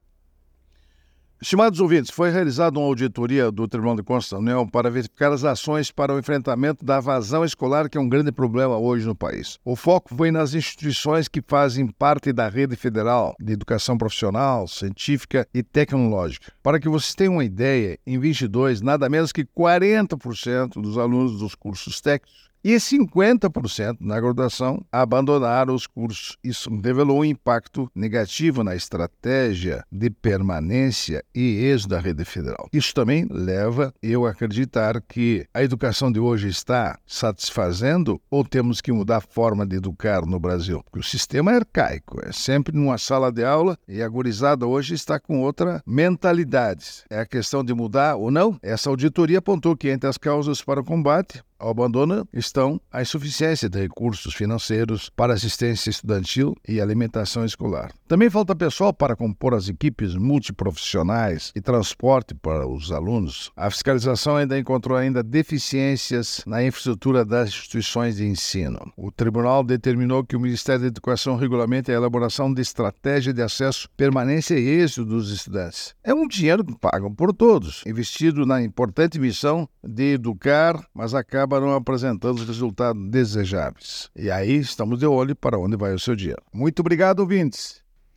É o assunto do comentário do ministro do Tribunal de Contas da União, Augusto Nardes, desta quarta-feira (03/07/24), especialmente para OgazeteirO.